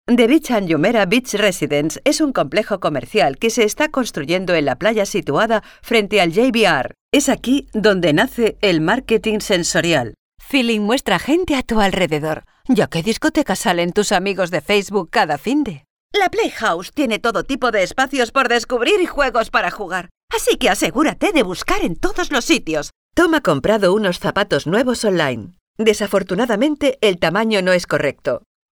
Sprechprobe: eLearning (Muttersprache):
Versatile, heart-warming and professional.
elearning_2.mp3